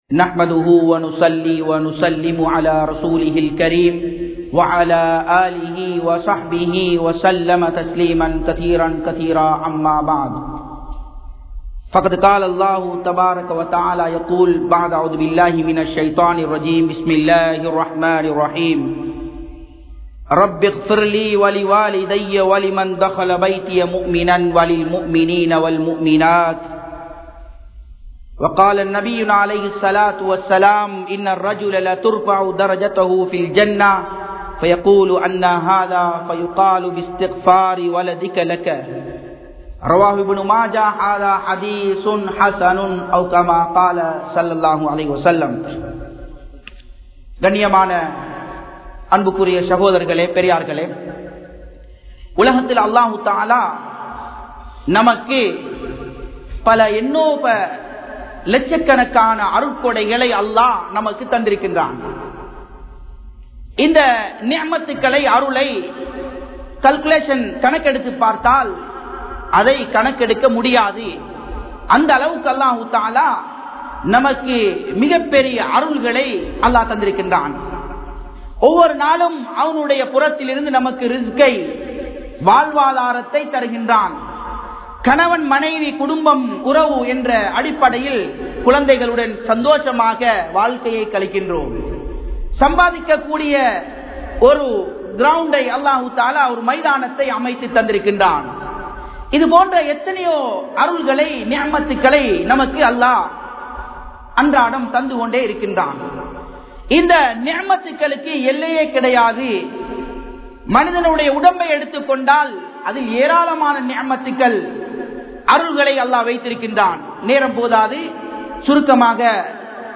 Pettroarhalin Sirappuhal (பெற்றோர்களின் சிறப்புகள்) | Audio Bayans | All Ceylon Muslim Youth Community | Addalaichenai
Nagoya Port Jumua Masjidh